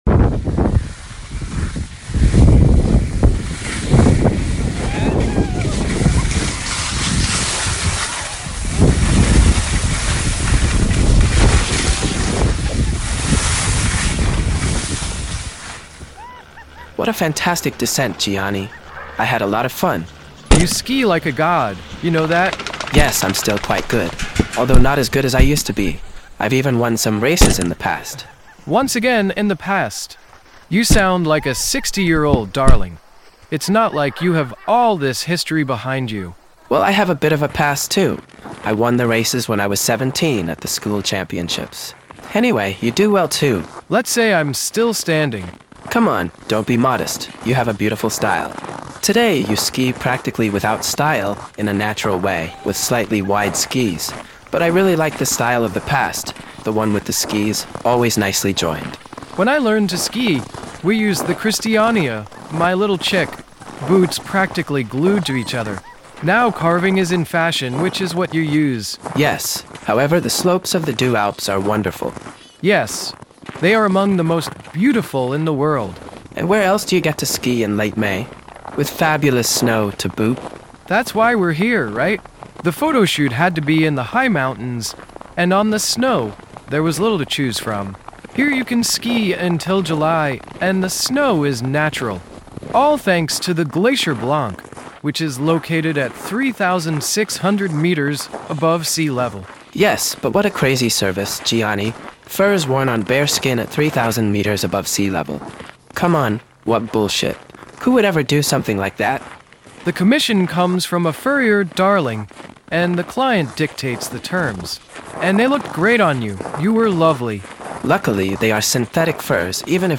We tried another little experiment in the experiment: Vincent, a new generation AI, plays all the roles in this episode: both Emmanuel and Gianni.